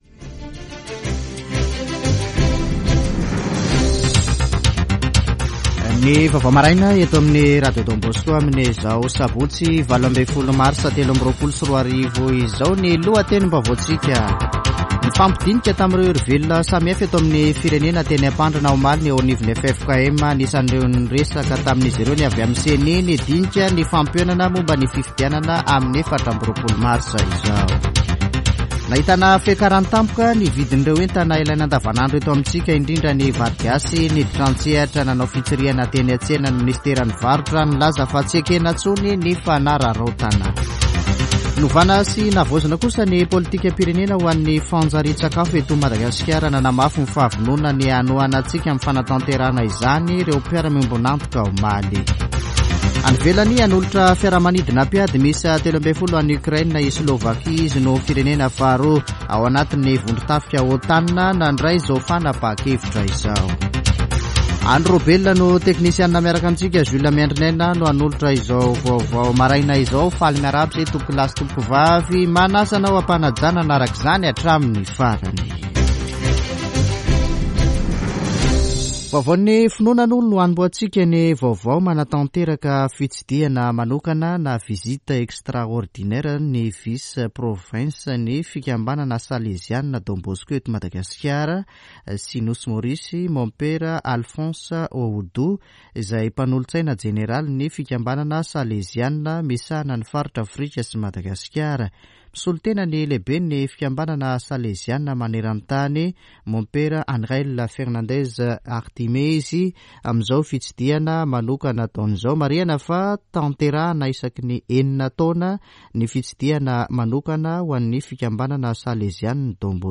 [Vaovao maraina] Sabotsy 18 marsa 2023